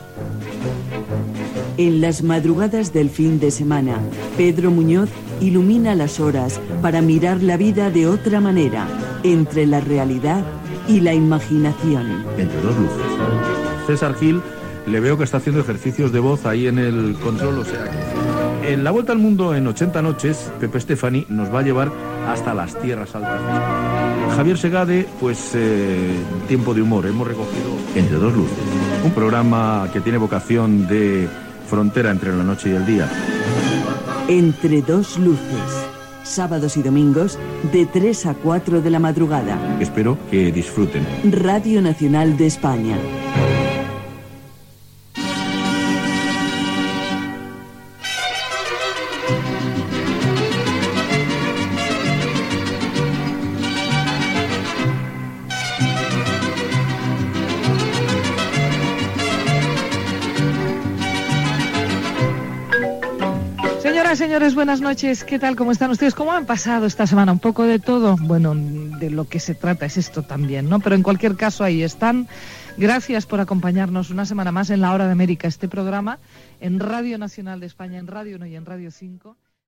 Promoció "Entre dos luces" i presentació del programa.
Divulgació